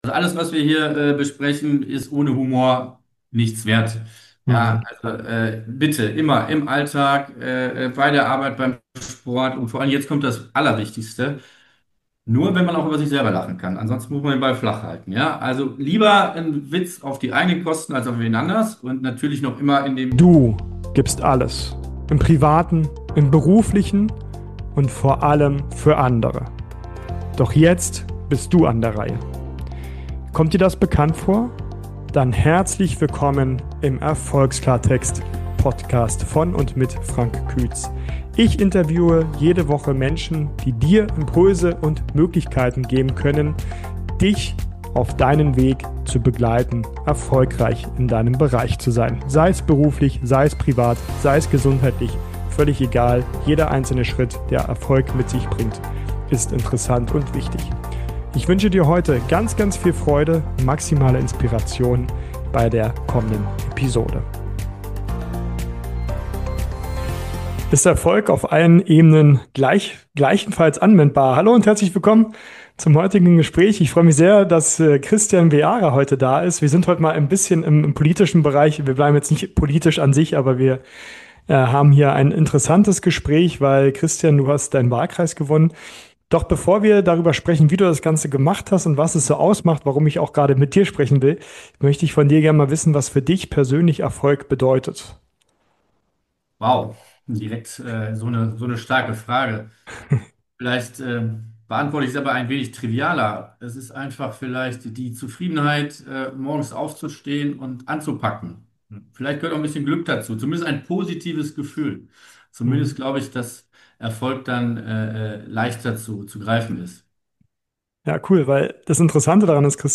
In dieser Folge spreche ich mit Kristian Beara (Polizeikommissar, Sicherheitsexperte und Kölner Stadtrat) über echte Erfolgsprinzipien jenseits von Parolen: Mut zur Entscheidung, sauberer Umgang mit Fehltritten, Frustrationstoleranz, Sport als Ausgleich, Humor als Superpower und Demut im Alltag. Du erfährst, warum Niederlagen oft die besten Trainer sind, wie man Diskussionen wertschätzend führt (auch wenn’s knirscht) und wieso Balance > Wissen ist, wenn es wirklich darauf ankommt. Plus: der Moment des Wahlsiegs – und was danach im Kopf passiert.